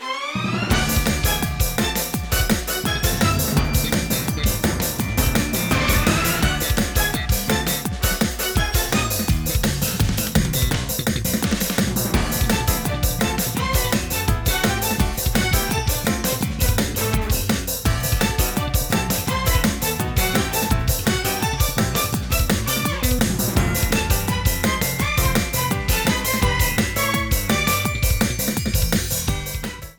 Frontrunning music
as a music sample